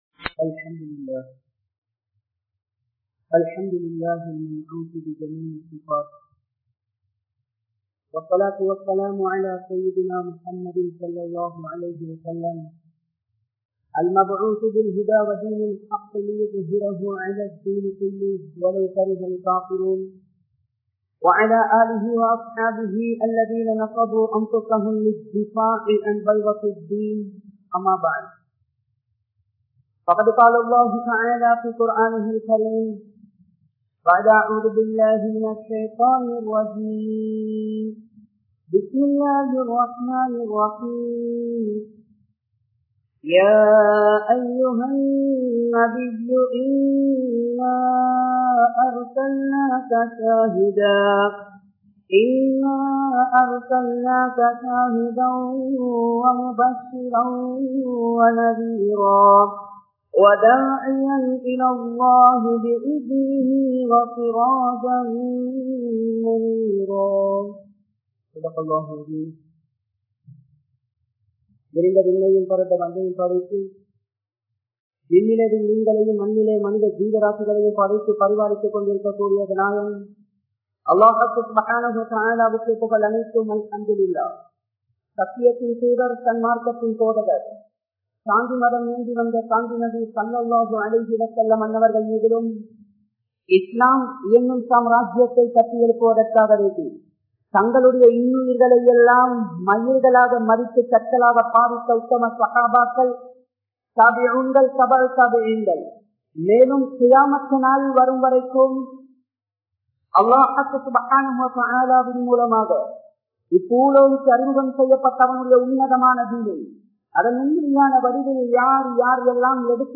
Nabi(SAW)Avarhalin Thiyaaham (நபி(ஸல்)அவர்களின் தியாகம்) | Audio Bayans | All Ceylon Muslim Youth Community | Addalaichenai
Muhiyadeen Jumua Masjidh